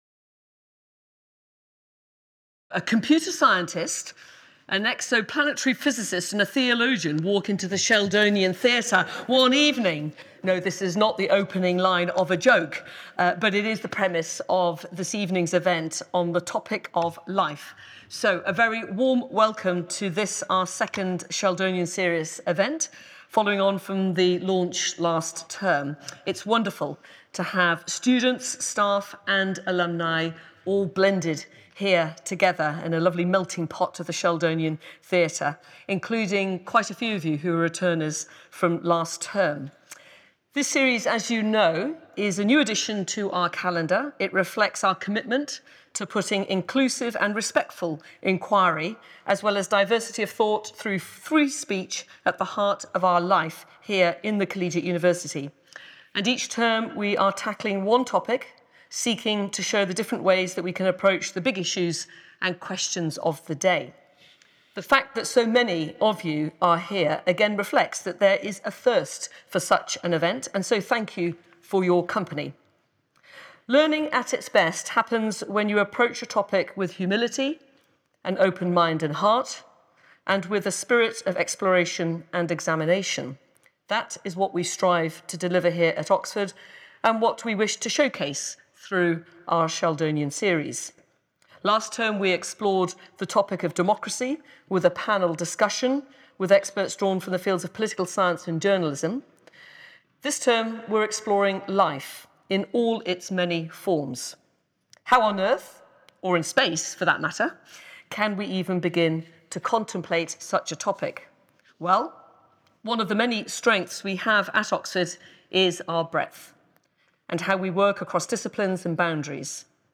‘Are we alone in the universe?’ and ‘What does it mean to be human?’ were among the questions explored when another large audience of staff, students and alumni gathered at the Sheldonian Theatre to enjoy the University’s second Sheldonian Series event on Tuesday 11 February.